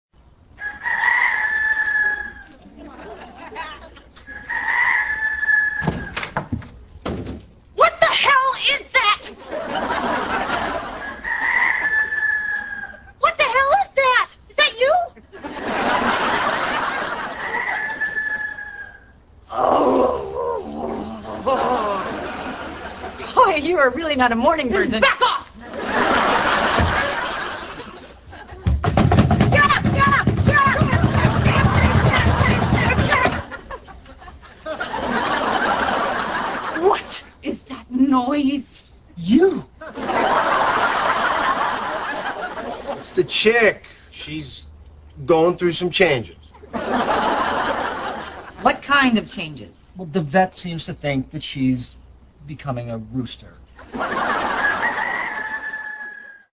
一天早上，Rachel被一阵鸡叫声给吵醒了：